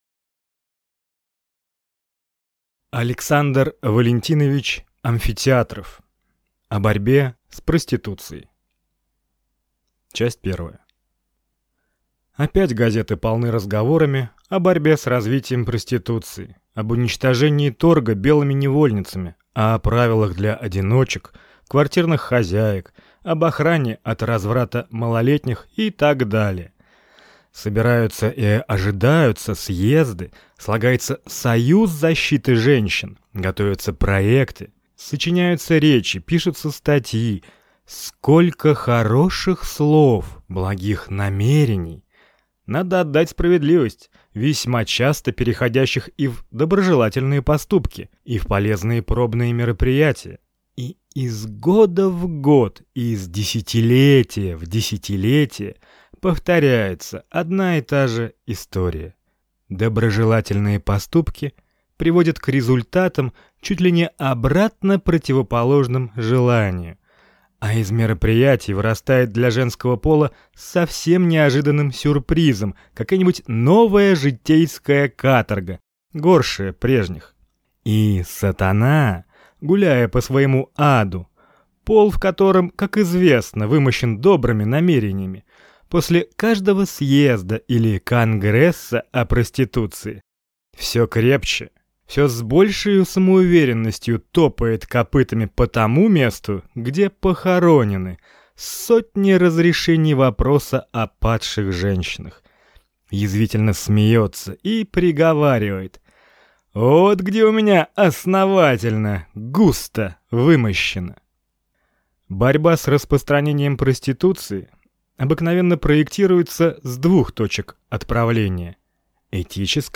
Аудиокнига О борьбе с проституцией | Библиотека аудиокниг